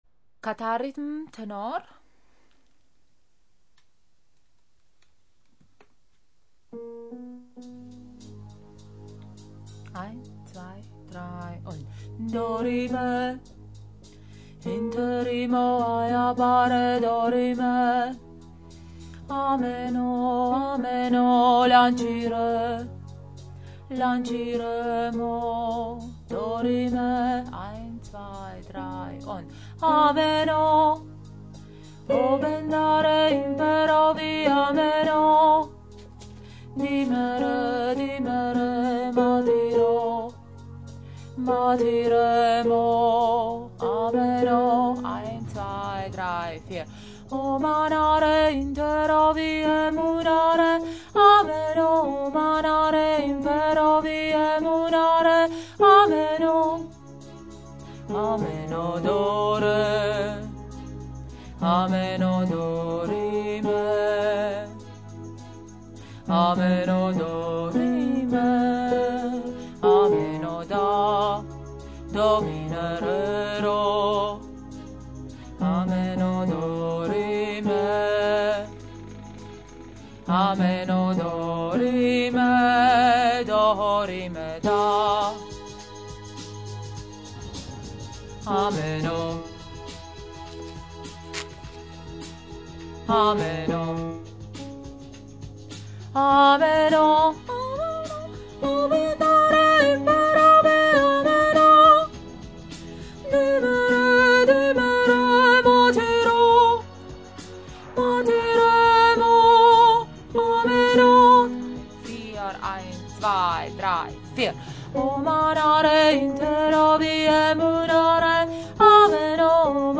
Ameno – Tenor